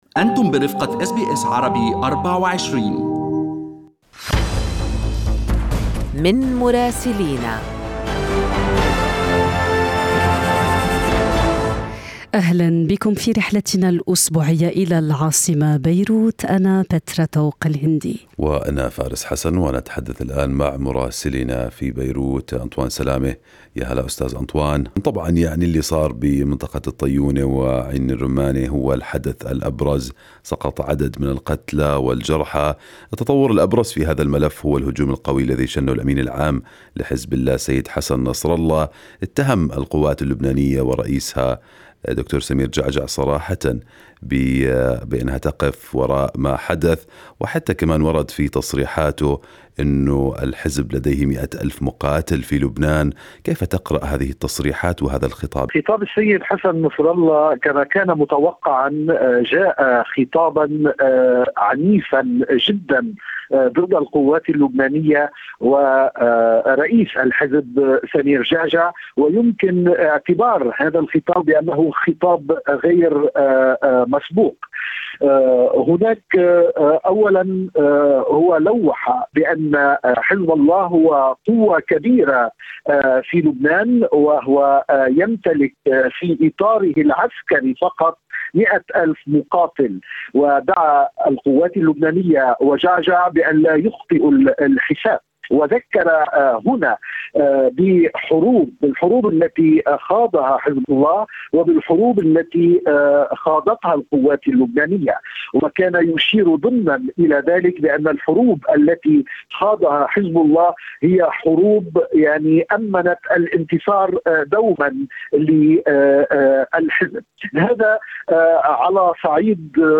من مراسلينا: أخبار لبنان في أسبوع 19/10/2021